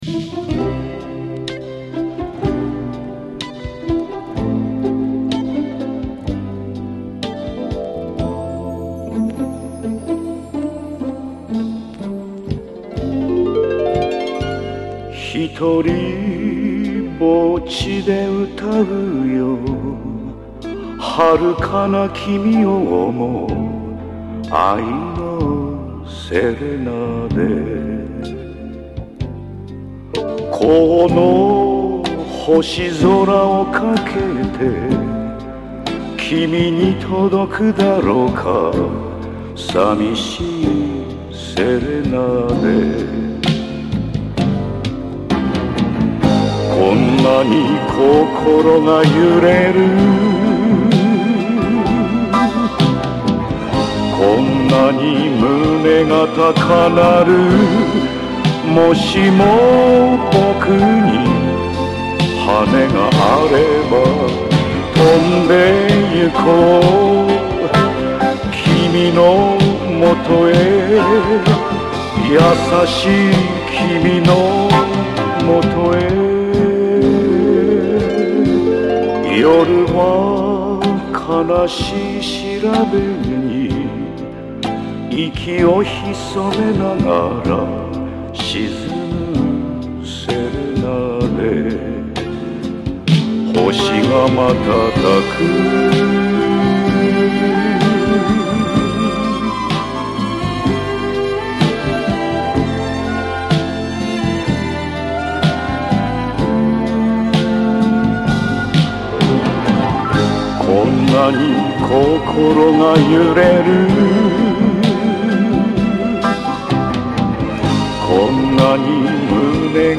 声も渋い！